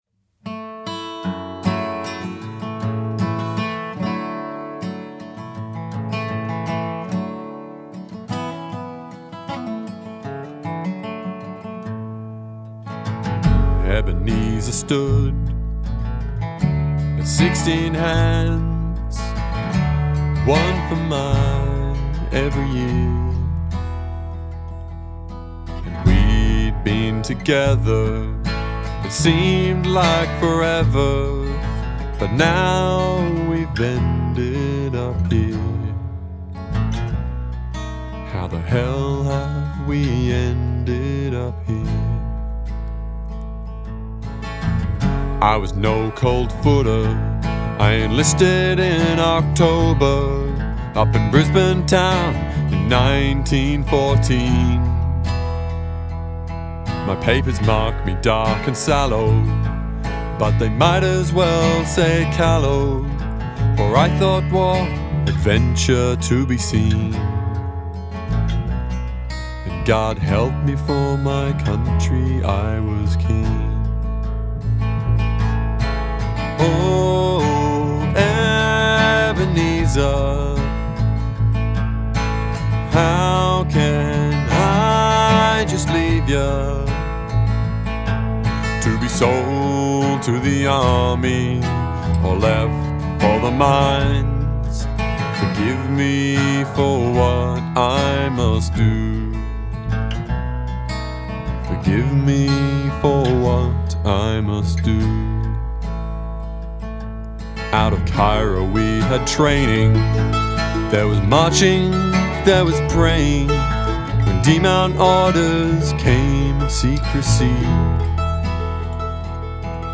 ANZAC ballad